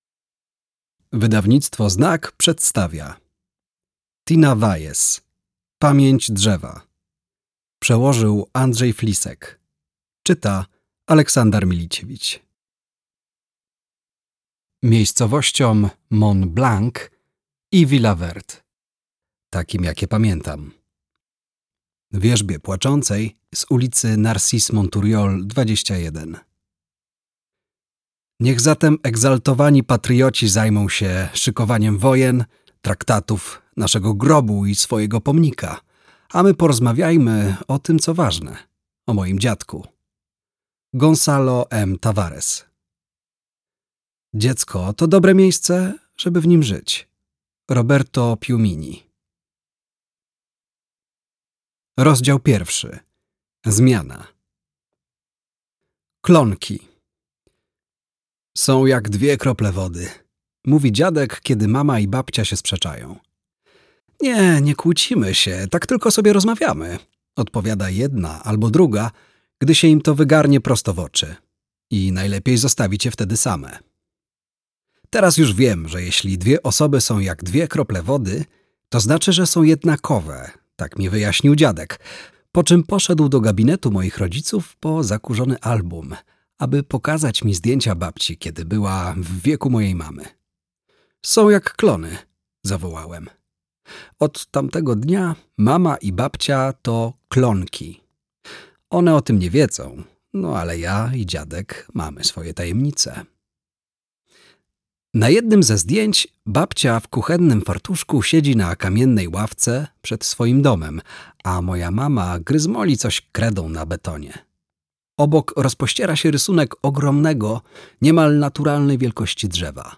Pamięć drzewa. Pamięć drzewa - Tina Valles - audiobook